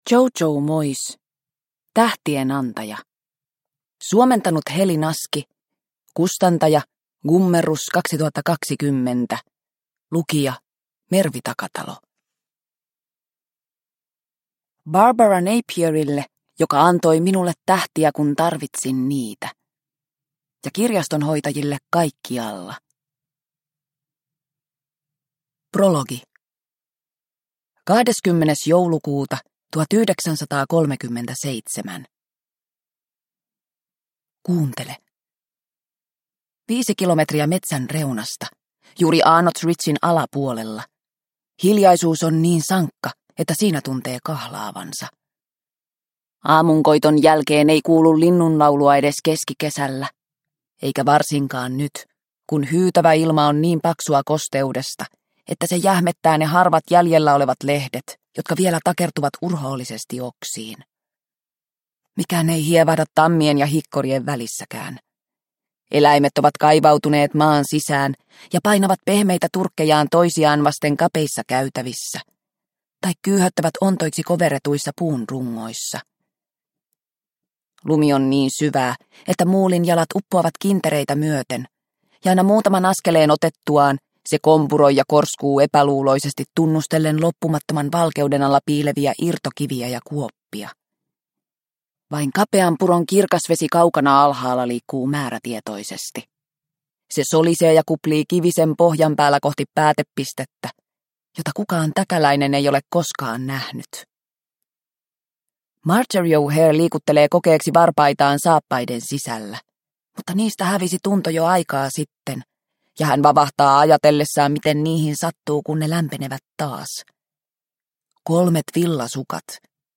Tähtien antaja – Ljudbok – Laddas ner